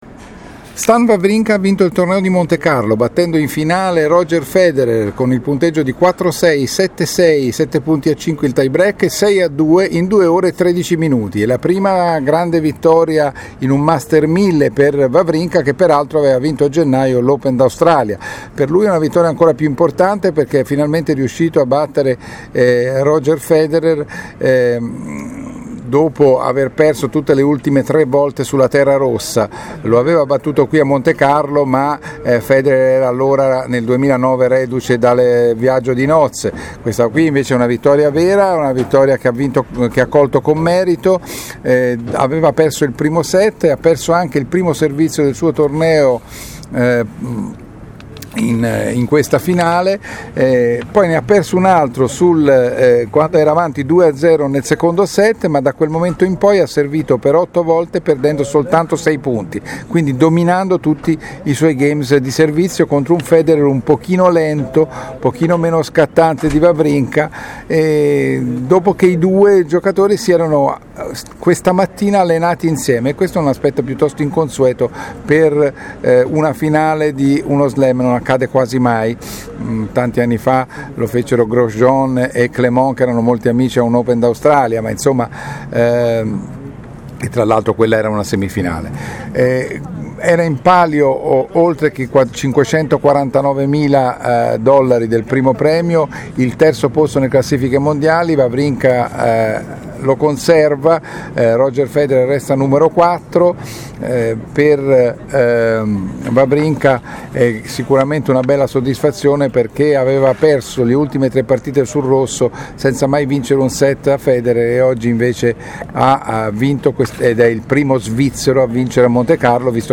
Il commento